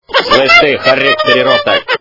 При прослушивании Из м.ф. Ледниковый период - Слышь, ты, хорек переросток качество понижено и присутствуют гудки.